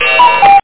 CuckooClock.mp3